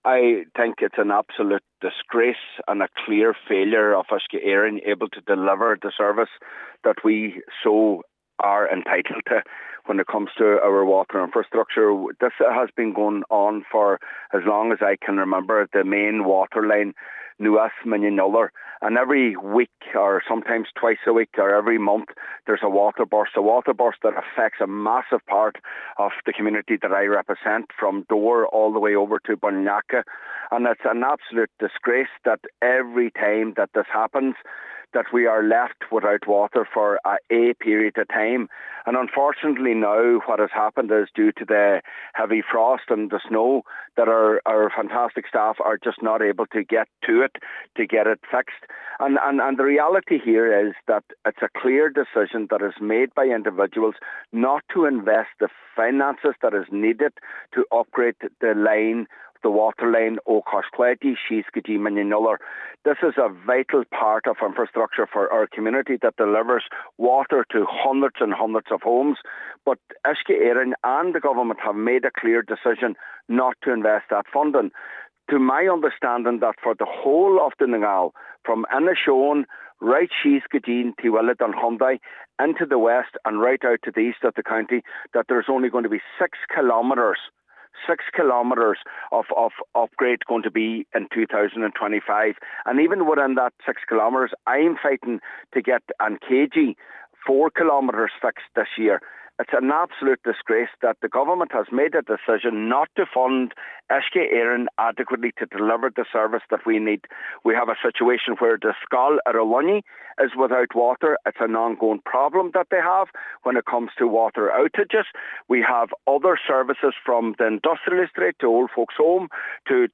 Councillor Micheal Choilm MacGiolla Easbuig says burst water mains in the area have become a weekly occurrence.